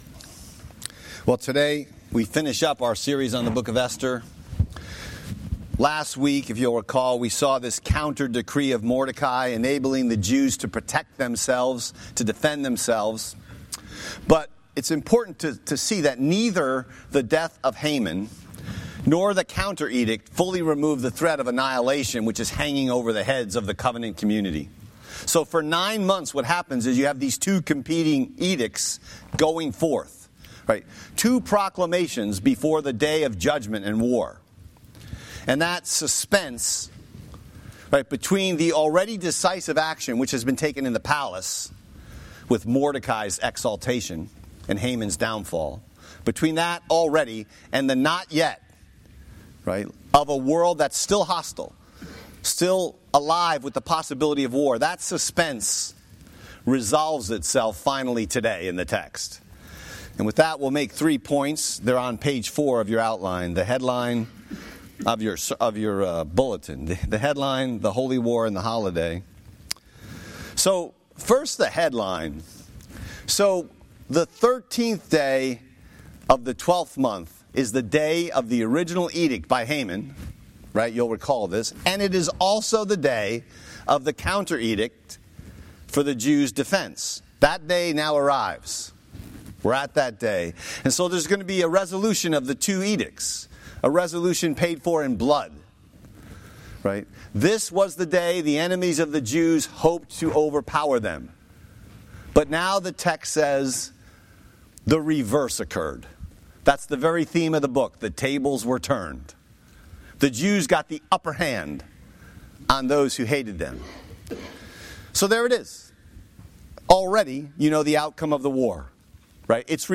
Message Text: Esther 9:1-19